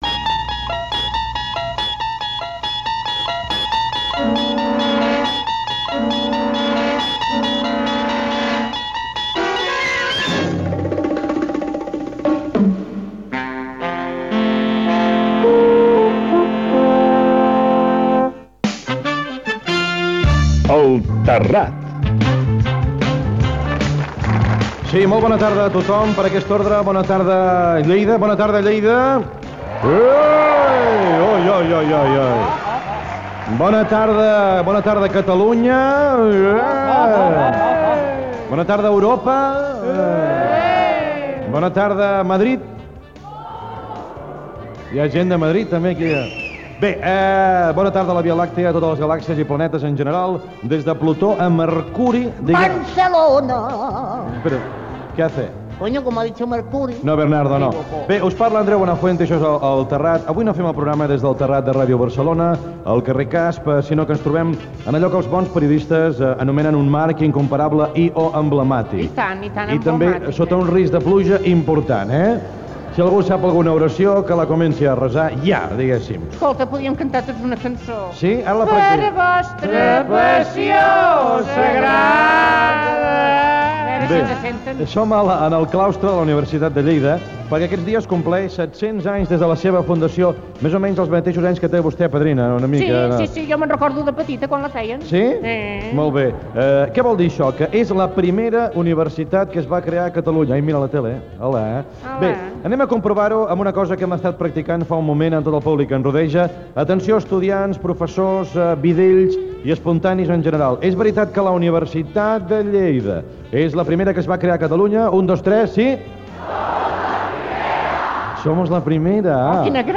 Careta del programa, presentació del programa fet des del Claustre de la Pensativa de la Universitat de Lleida
Entreteniment